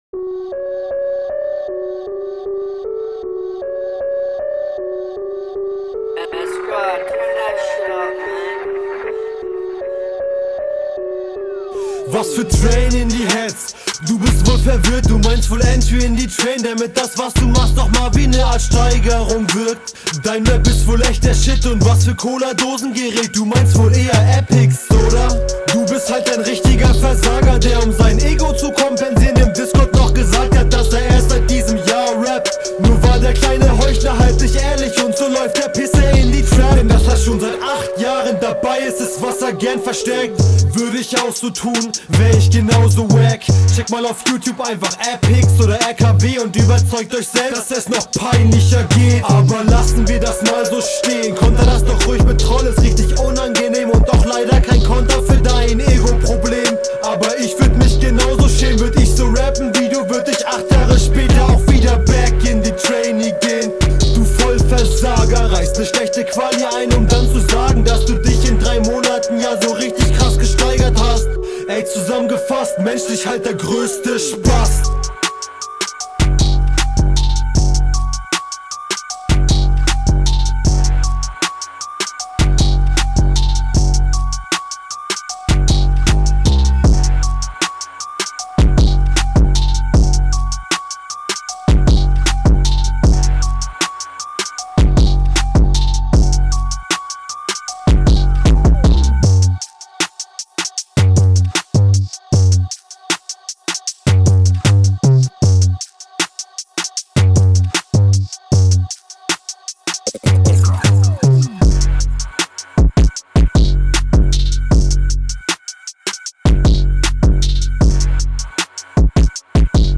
Flow wieder gut, ein paar gute Reime dabei aber noch zu unkonstant.